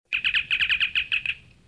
Star Trek Communicator